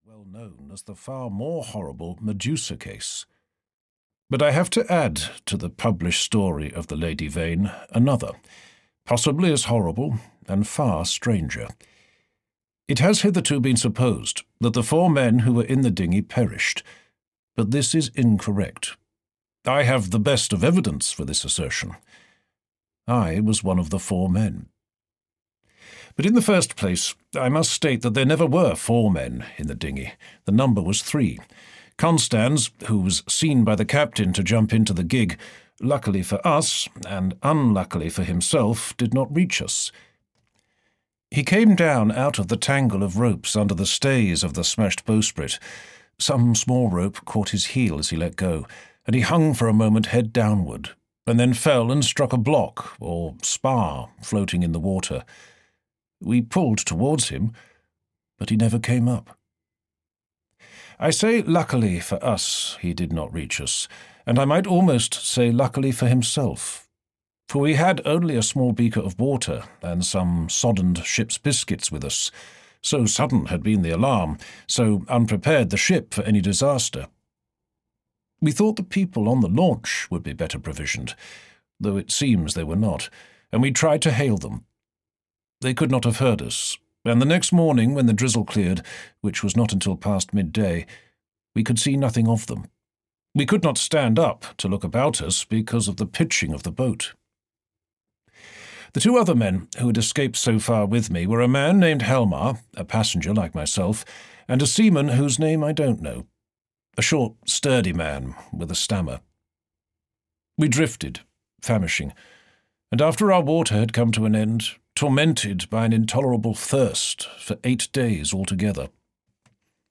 The Island of Doctor Moreau (EN) audiokniha
Ukázka z knihy